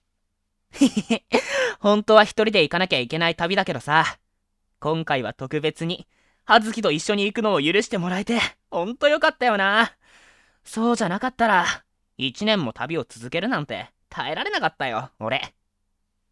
・元気で明るく行動力がある
【サンプルボイス】